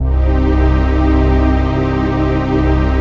DM PAD2-93.wav